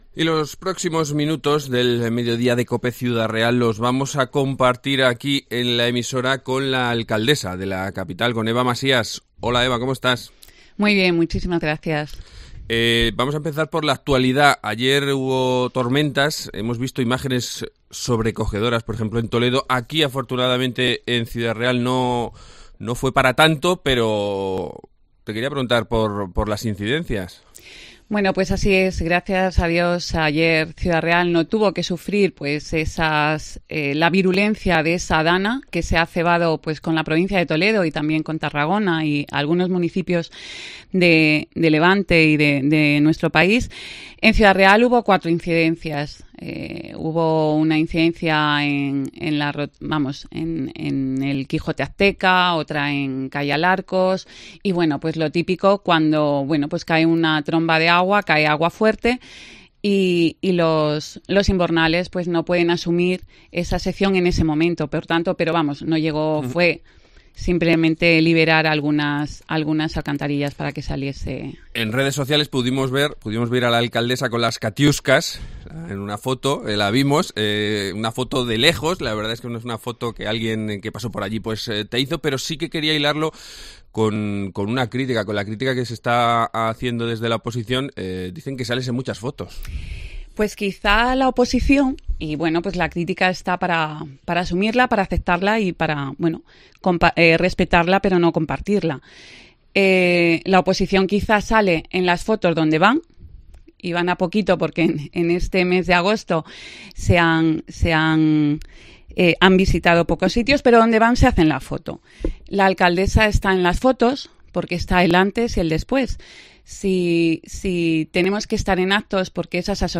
Eva Masías, alcaldesa de Ciudad Real
Entrevista